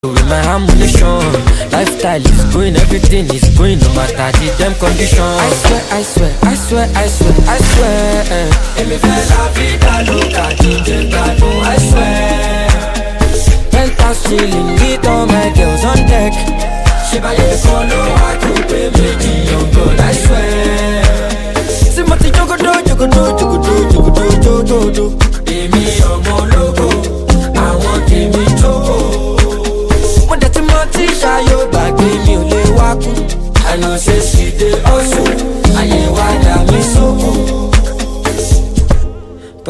Rap/Hip Hop